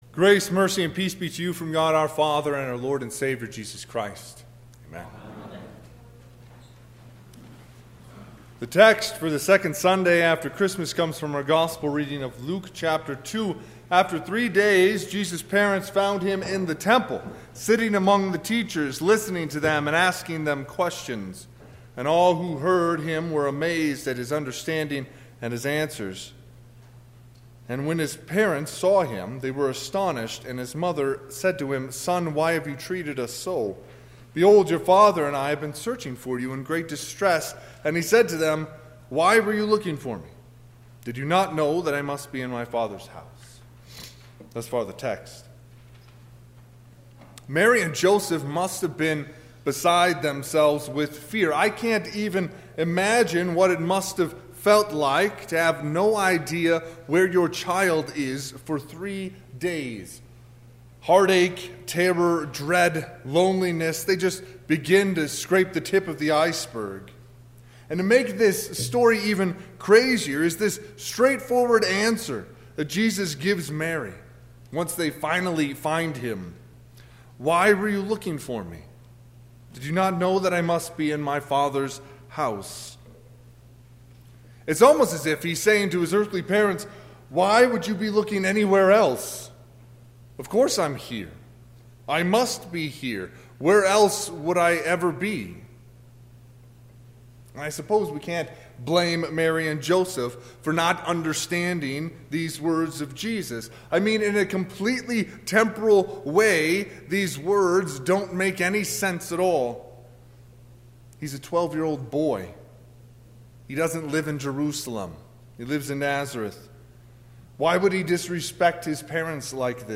Sermon - 1/5/2020 - Wheat Ridge Evangelical Lutheran Church, Wheat Ridge, Colorado
Second Sunday after Christmas